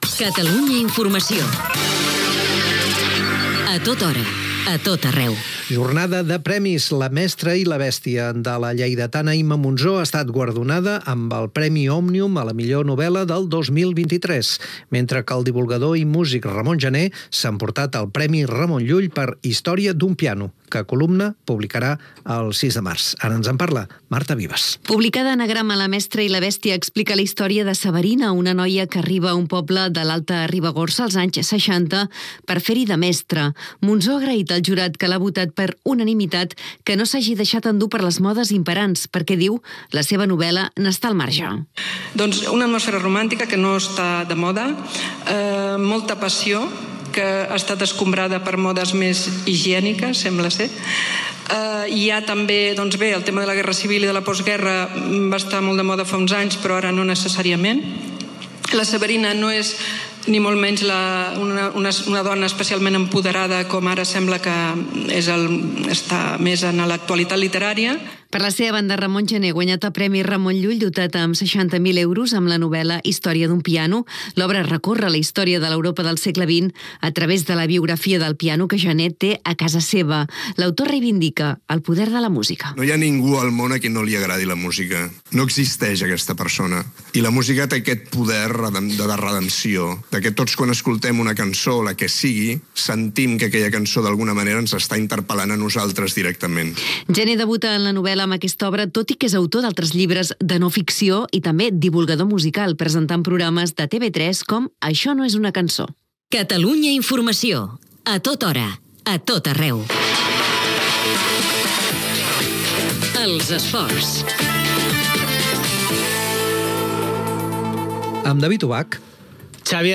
Junts tomba l'aprovació de la Llei d'Amnistia al Congrés espanyol, regulació de l'ús dels telèfons mòbils a les escoles catalanes, baix ús del català a les aules escolars Gènere radiofònic Informatiu